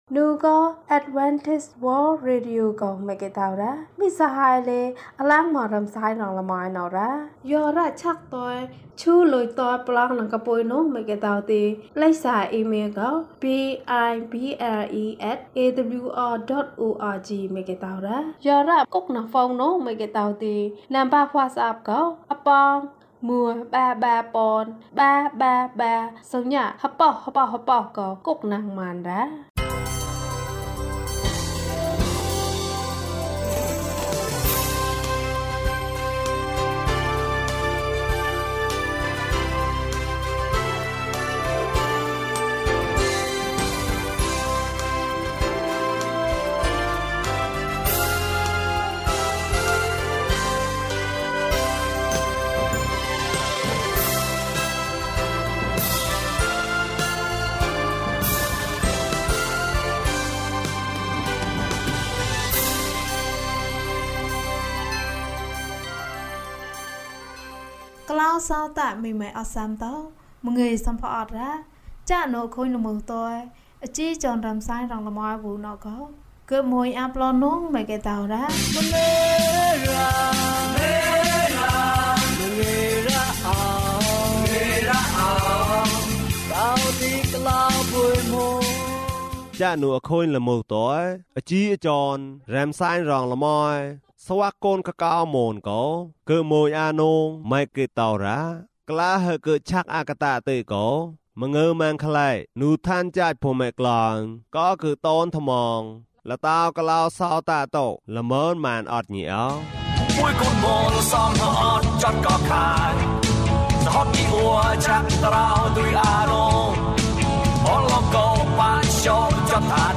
အလင်းလူ။ ကျန်းမာခြင်းအကြောင်းအရာ။ ဓမ္မသီချင်း။ တရားဒေသနာ။